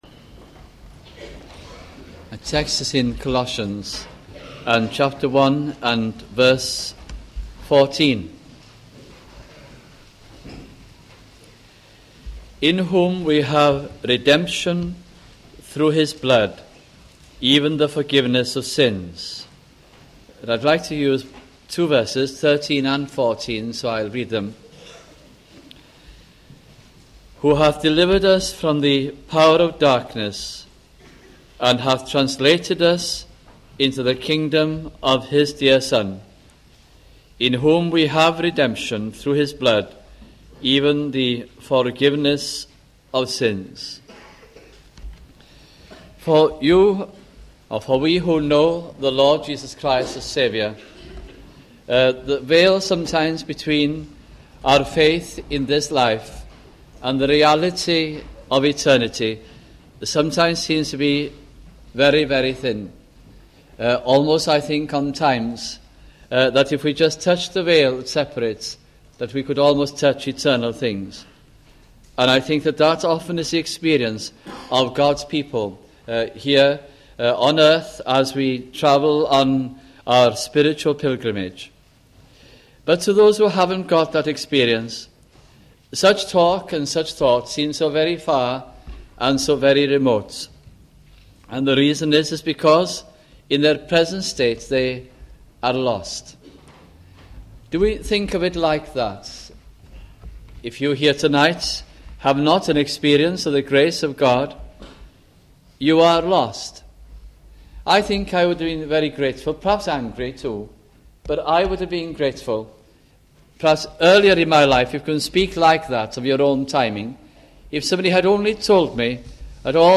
» Colossians Gospel Sermons